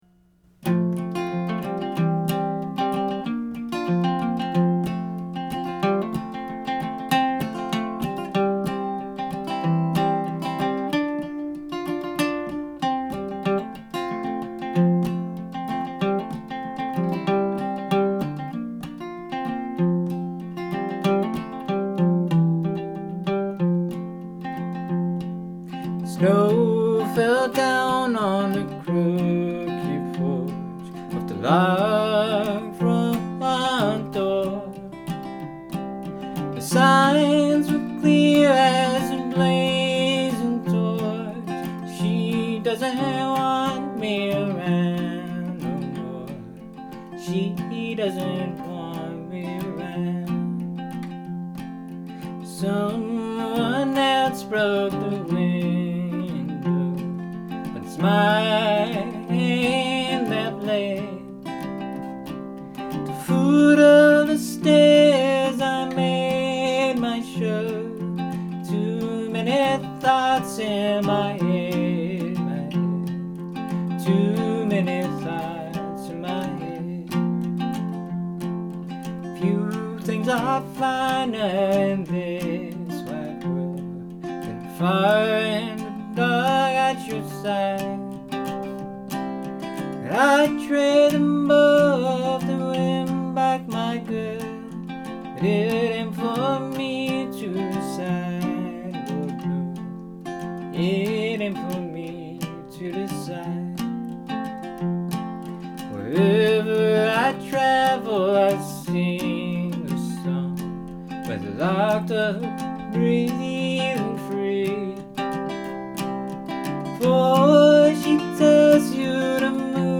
Country
Folk